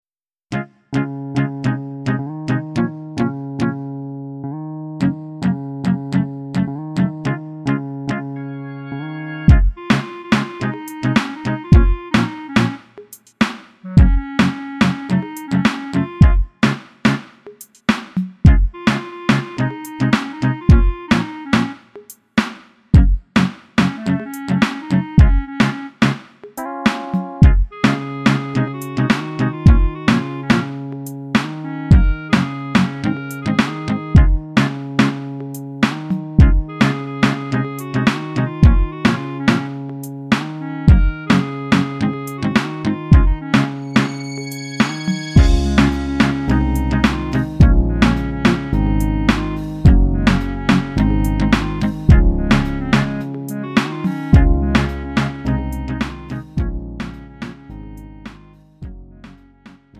음정 -1키 3:31
장르 구분 Lite MR